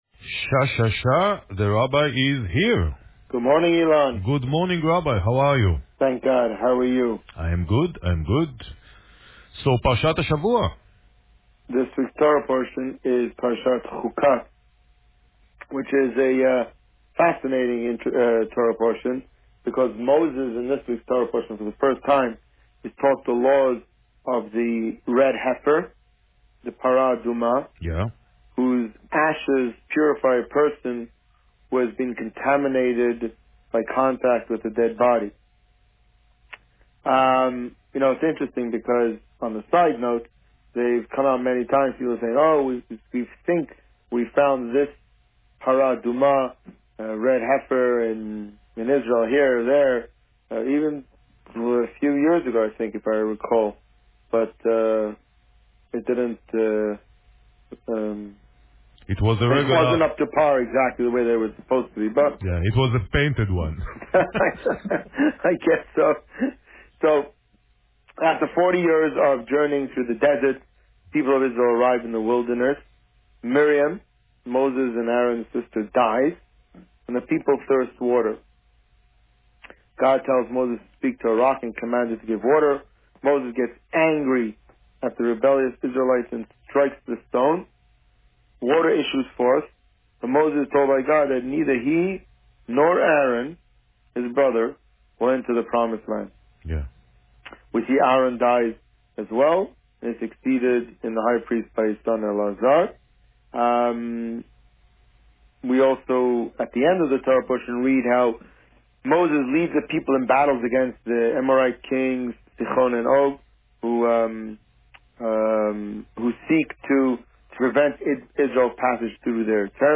This week, the Rabbi spoke about Parsha Chukat. Listen to the interview here.